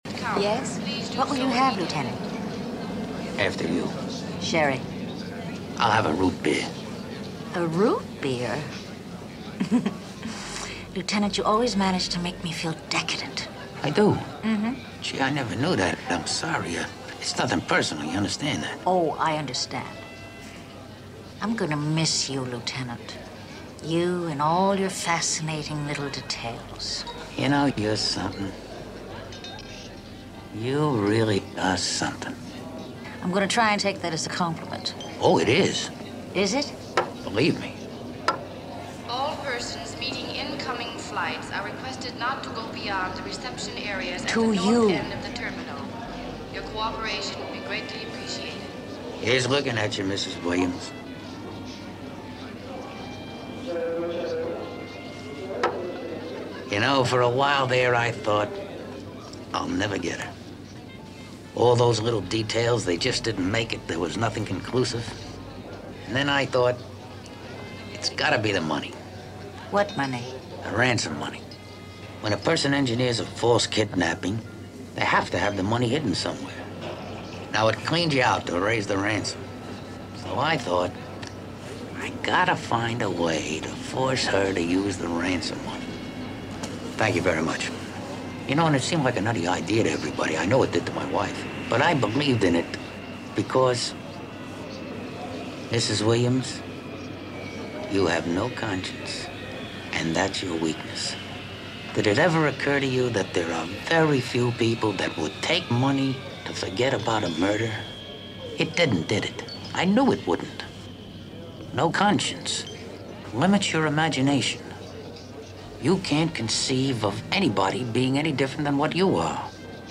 You can hear the seminal portion of Leslie’s and Columbo’s conversation here.